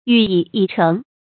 羽翼已成 yǔ yì yǐ chéng 成语解释 鸟的羽毛和翅膀已长全。比喻力量已经具备。